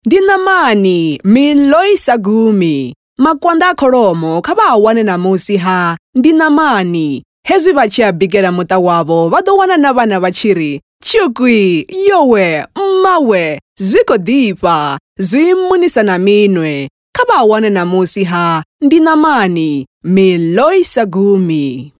authentic, authoritative, bright, captivating, commercial, empathic, resonant, soothing
With a naturally warm and articulate tone, she delivers everything from soulful narration to vibrant commercial reads with clarity and purpose.
TshivendaVO-bright.mp3